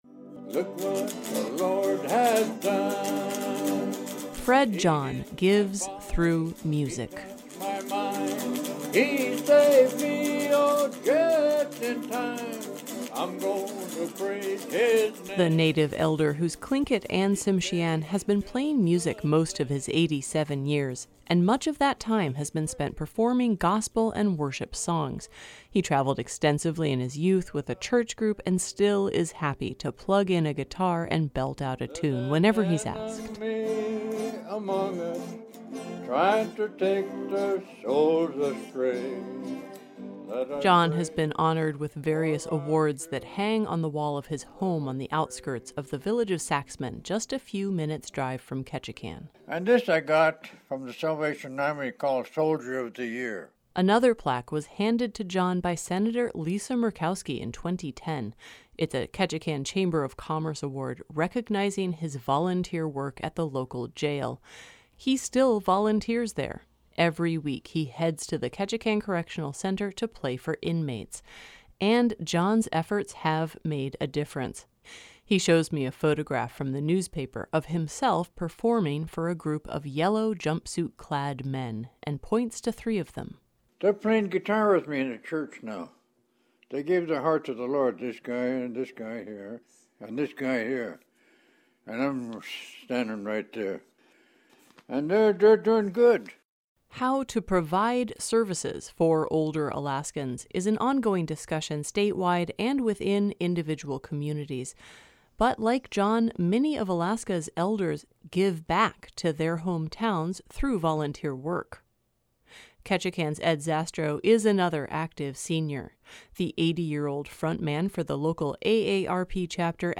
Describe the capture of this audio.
So, he put her on the phone.